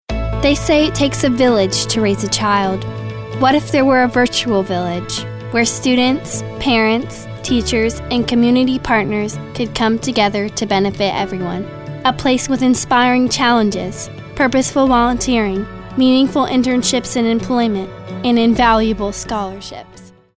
anti-announcer, caring, compelling, conversational, genuine, girl-next-door, informative, inspirational, kid-next-door, motivational, real, sincere, teenager, thoughtful, warm, young, younger